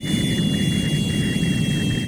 Deceleration1.wav